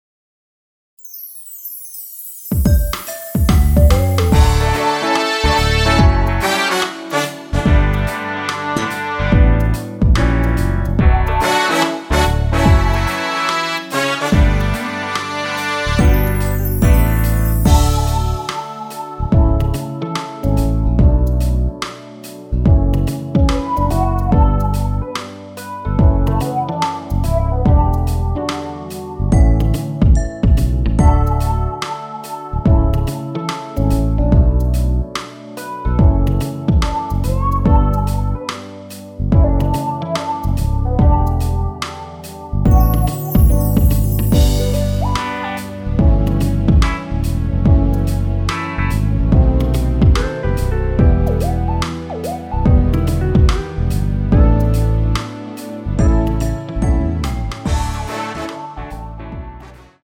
원키에서(-3)내린 MR입니다.
D
앞부분30초, 뒷부분30초씩 편집해서 올려 드리고 있습니다.
중간에 음이 끈어지고 다시 나오는 이유는